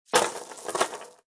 Descarga de Sonidos mp3 Gratis: cadena caida 1.